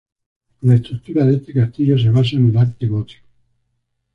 cas‧ti‧llo
/kasˈtiʝo/